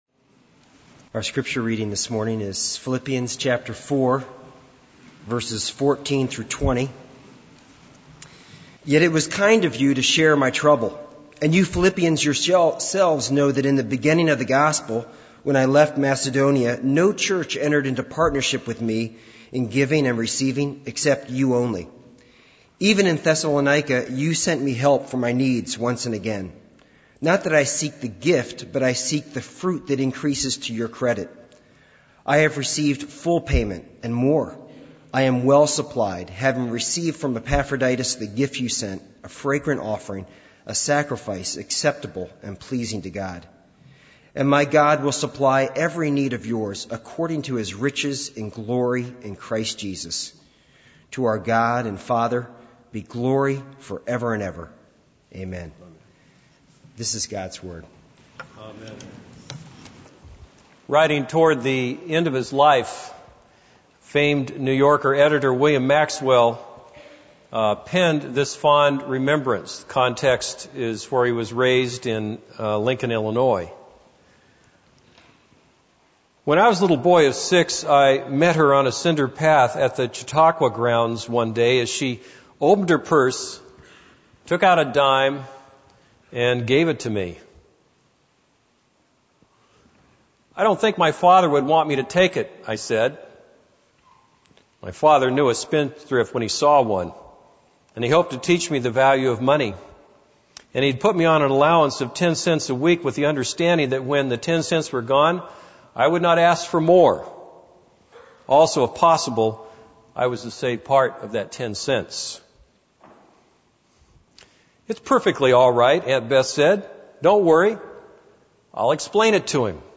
This is a sermon on Philippians 4:14-20.